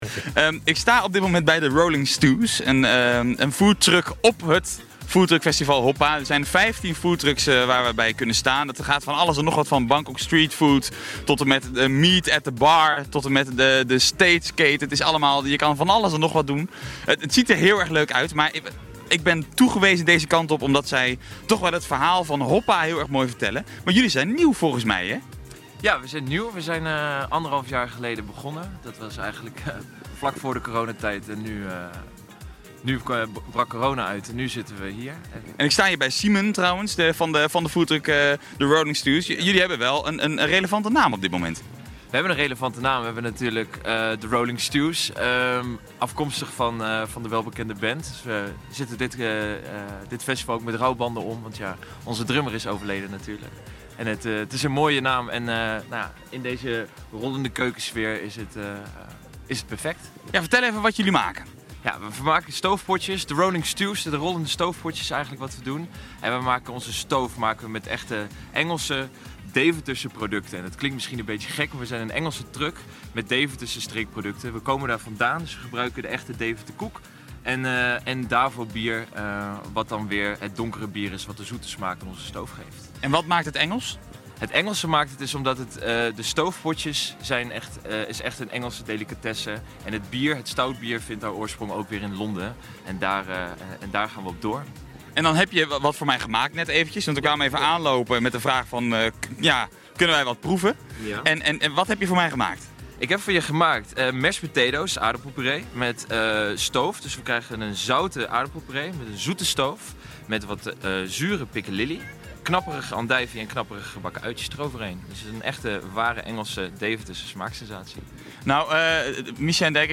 De Lunchclub - Live-verslag vanaf foodtruckfestival Hoppaaa! (deel 2)
lunchclub-live-verslag-vanaf-foodtruckfestival-hoppaaa-deel-2.mp3